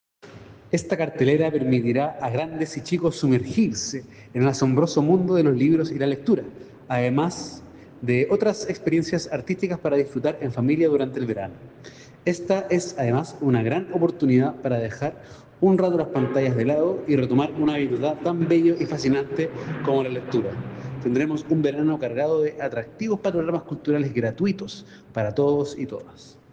En el Muelle Prat de Valparaíso se realizó el lanzamiento regional de “Biblioverano”, iniciativa anual organizada por el Ministerio de las Culturas, las Artes y el Patrimonio, a través del Servicio Nacional del Patrimonio Cultural, que busca promover la participación de la comunidad en actividades gratuitas de fomento lector y extensión cultural realizadas por bibliotecas y servicios bibliotecarios públicos y privados en todo Chile.
Javier Esnaola, seremi de las Culturas, destacó que esta cartelera